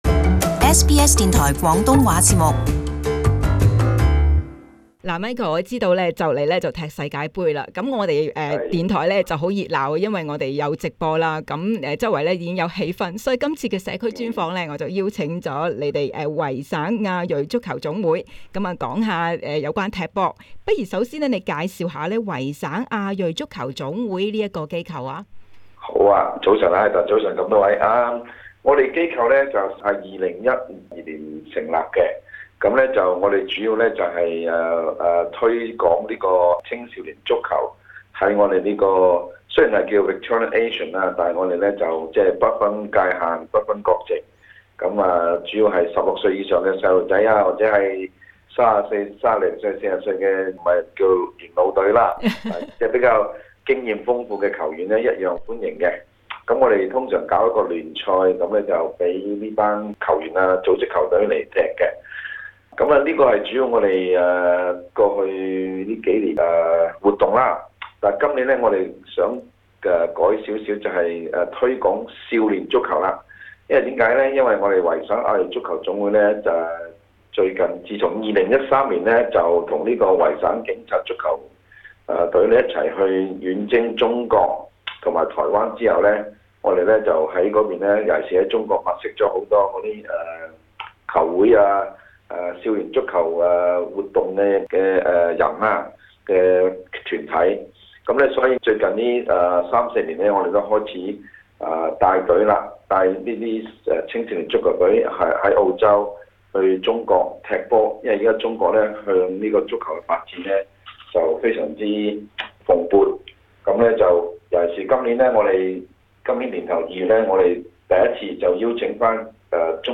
【社区专访】维省亚矞足球总会